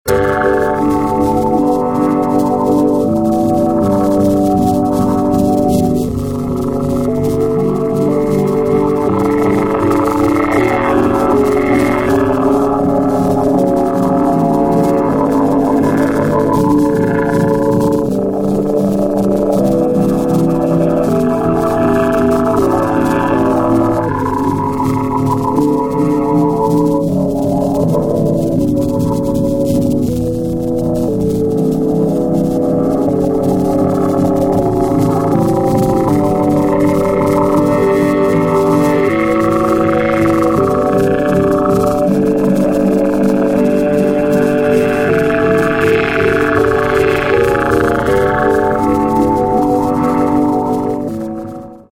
developed senses of melodies and the manners of dub